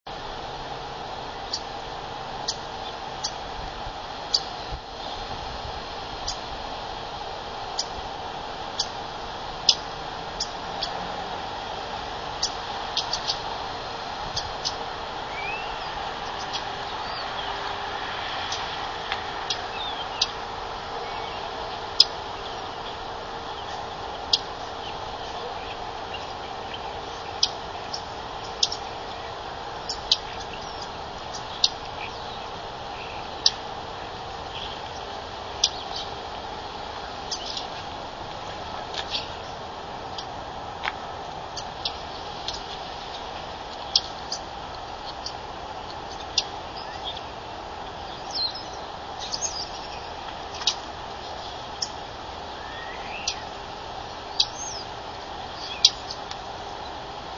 Common Yellowthroat
Perth Amboy, New Jersey, parking lot behind condo near Arthur Kill River, 9/25/04, eating seeds from three foot Lambsquarters behind parking lot with Starlings in background (224kb)